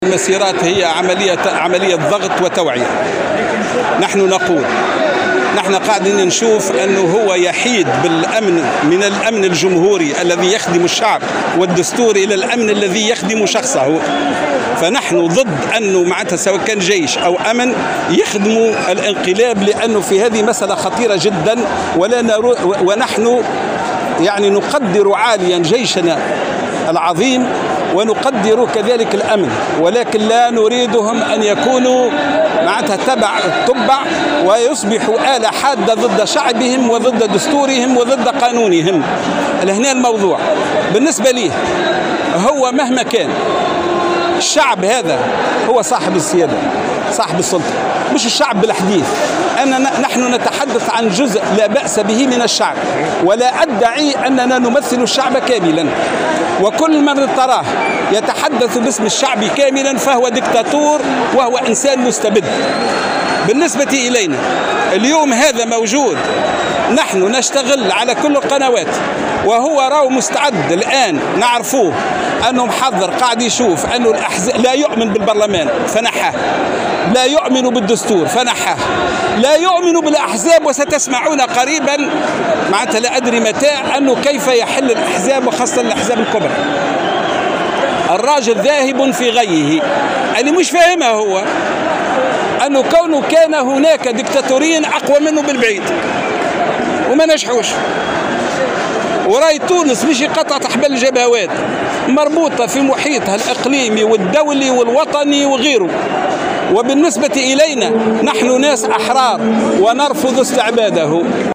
قال القيادي بحركة النهضة سيد الفرجاني، خلال مسيرة لمعارضي قرارات الرئيس قيس سعيّد، بتونس العاصمة، إن رئيس الجمهورية الذي لا يؤمن بالدستور فعطّله، ولا يؤمن بالبرلمان فجمد نشاطاته، كما أنه لا يؤمن بالأحزاب وقد يتجه نحو حلها وخاصة منها "الأحزاب الكبرى".
وحذر الفرجاني، في تصريح لمراسل الجوهرة أف أم، اليوم الأحد، من ممارسات رئيس الدولة الذي يحيد بالأمن الجمهوري والجيش الوطني، ليخدما شخصه وانقلابه بدلا عن حماية مصالح الشعب، بحسب تعبيره.